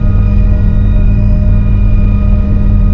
BACKGROUND / LIFT